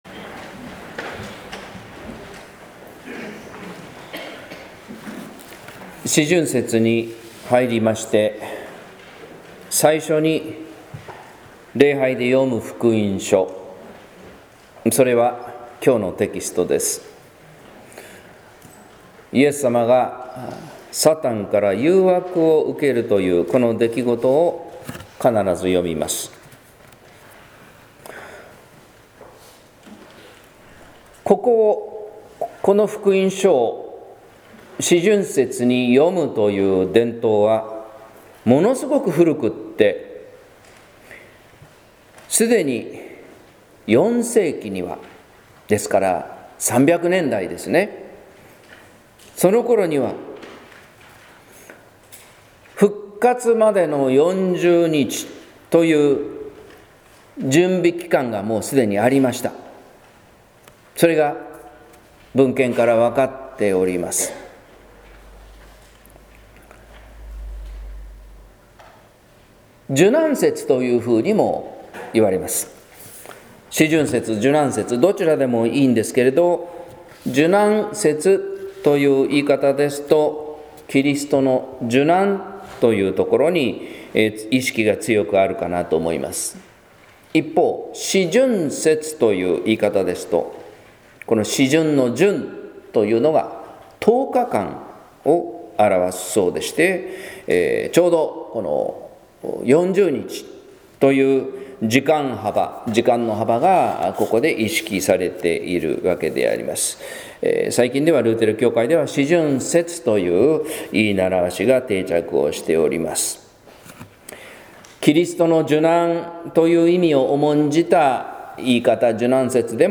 説教「サタンの挑発」（音声版）